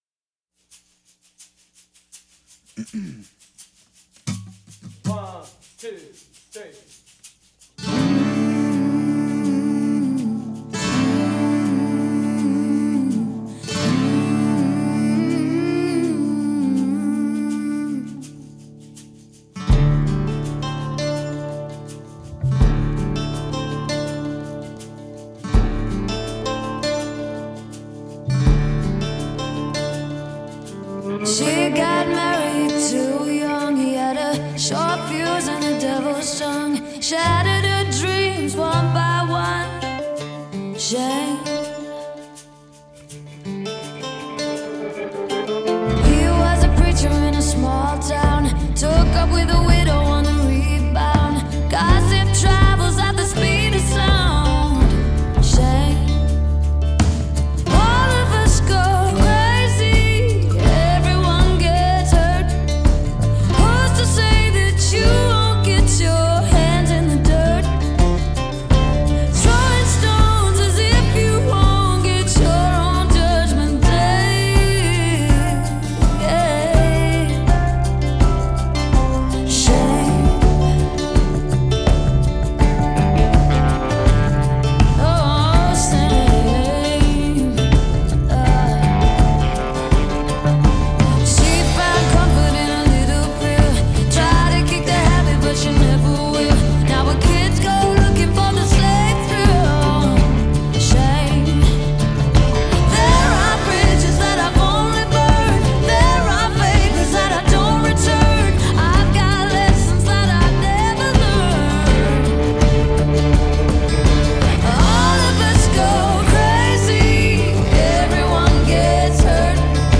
Genre: pop, female vocalists, singer-songwriter